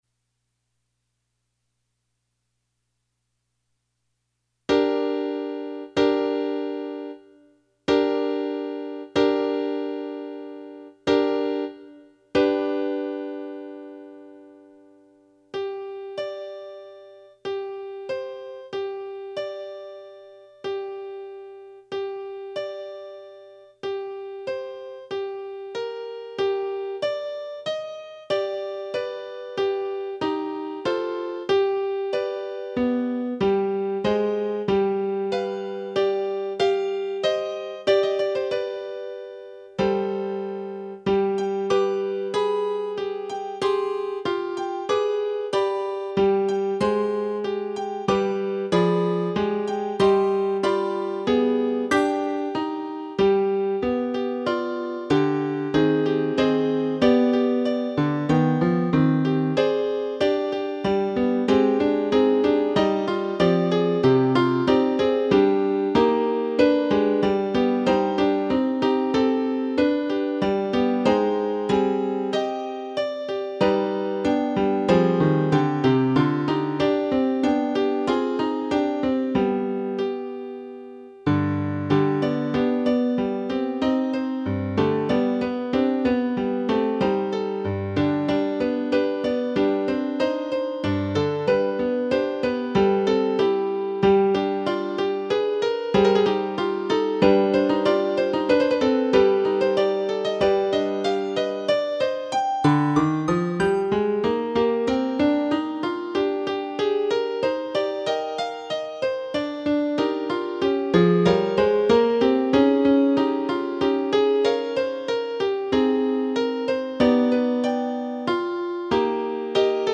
- Nothing looks true in this slow, turbulent journey